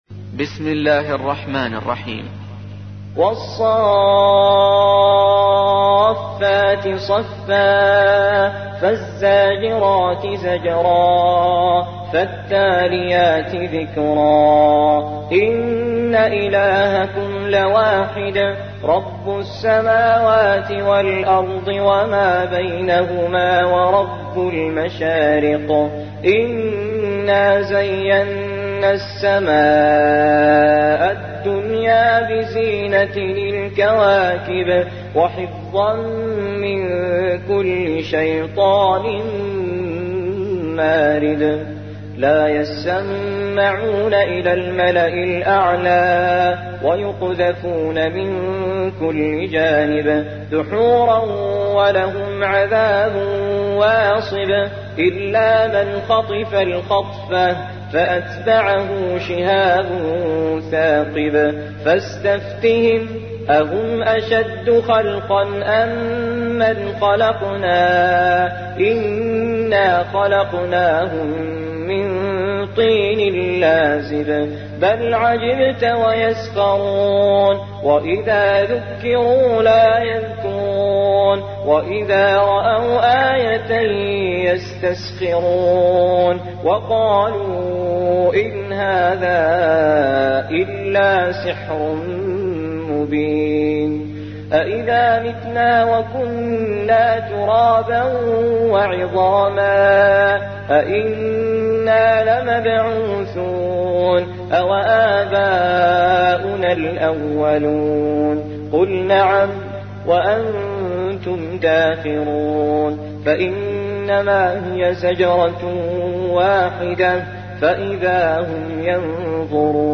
37. سورة الصافات / القارئ